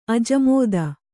♪ ajamōda